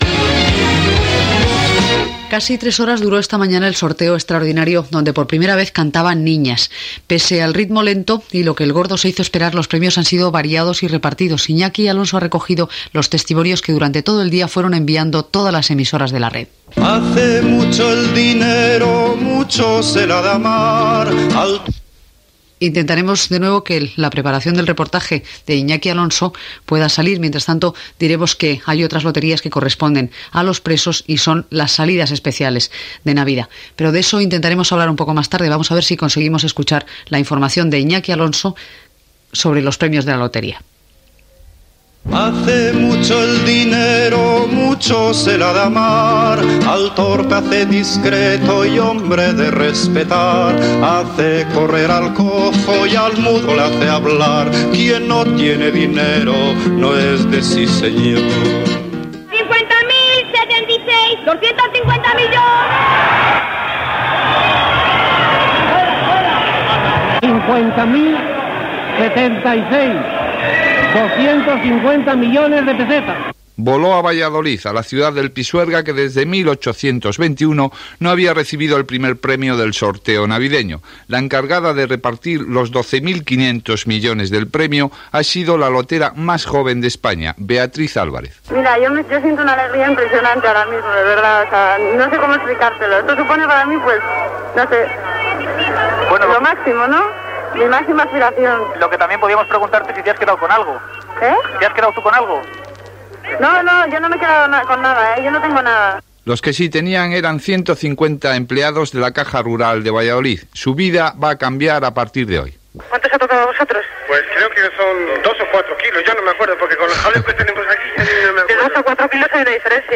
Informació sobre els agraciats i la primera aparició de les nenes per cantar els números de la rifa del sorteig de Nadal
Informatiu